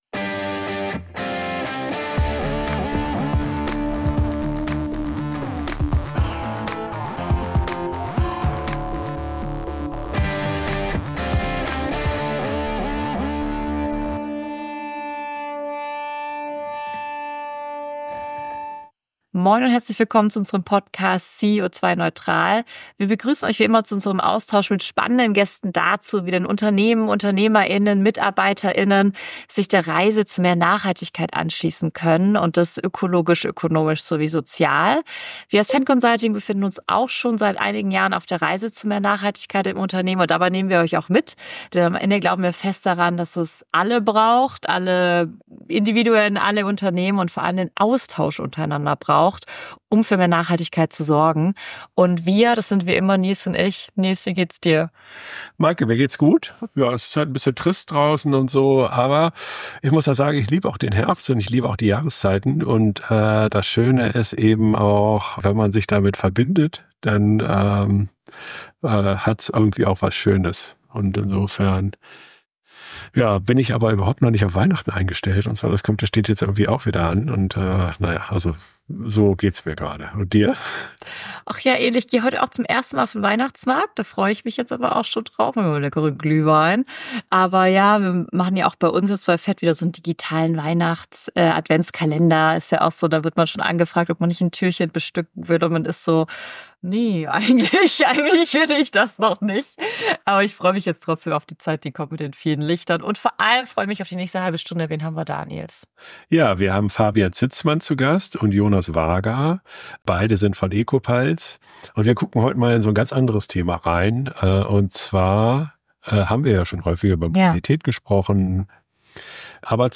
Wie kommt man als Start Up an öffentliche Aufträge? | mit Ecopals ~ CEO2-neutral - Der Interview-Podcast für mehr Nachhaltigkeit im Unternehmen Podcast